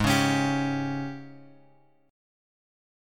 G# Major 7th Suspended 4th
G#M7sus4 chord {4 4 x x 2 3} chord